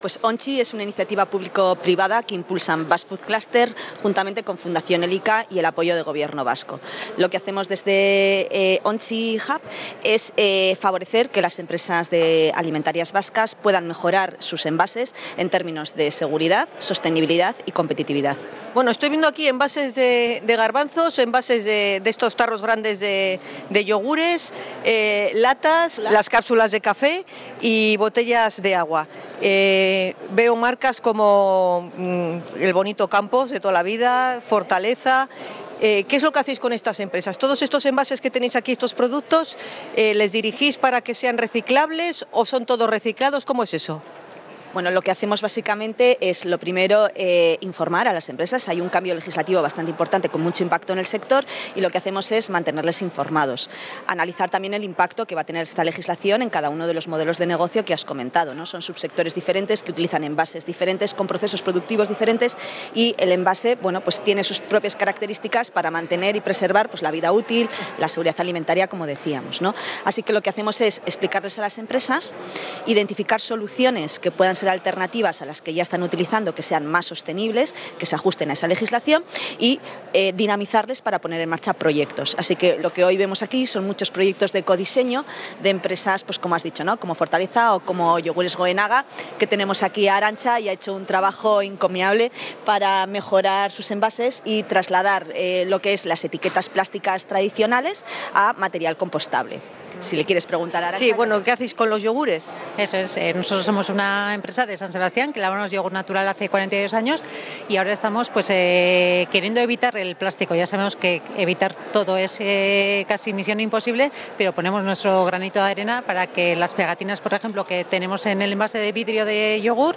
Hablamos con varios expositores que muestran sus propuestas en el Euskalduna Bilbao